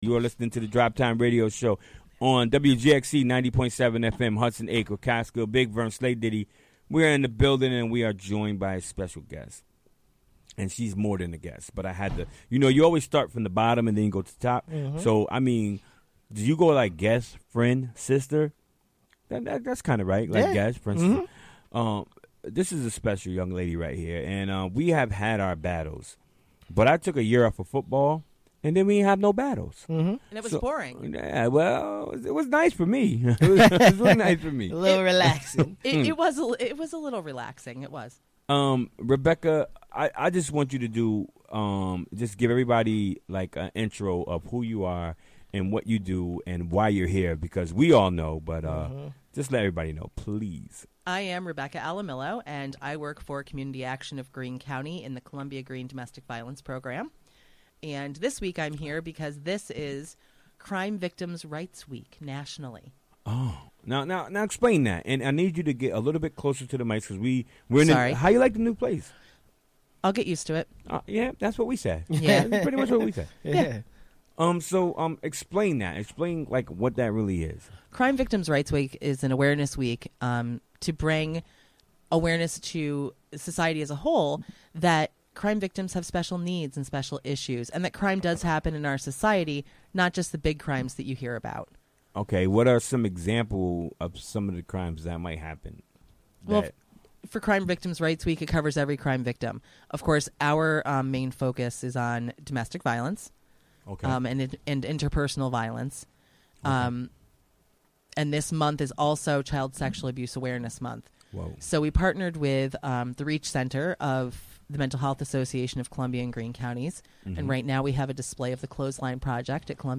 Recorded live during the WGXC Afternoon Show Wednesday, April 11, 2018.